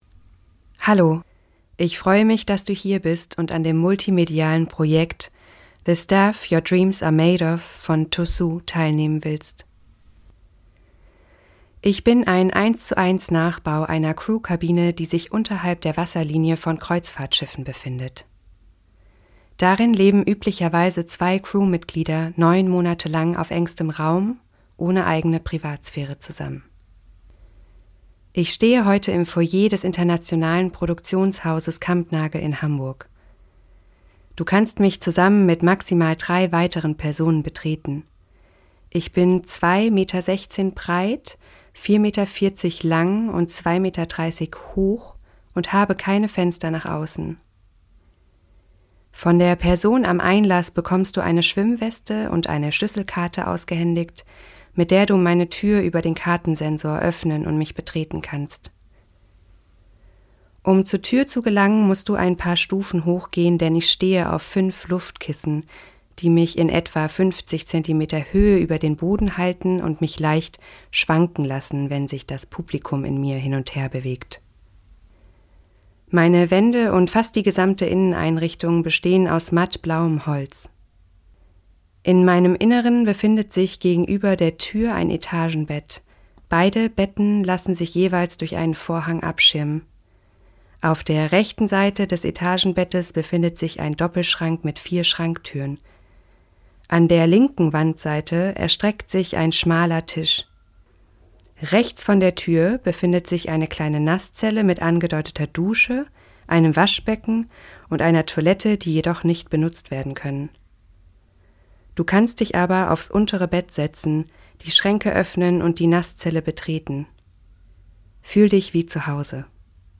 TÒ SU / Mahlknecht Prinoth: The Staff Your Dreams Are Made Of – Audiodeskription Deutsch
Hier versammeln wir eingesprochene Abendzettel und Audioflyer für Stücke auf Kampnagel.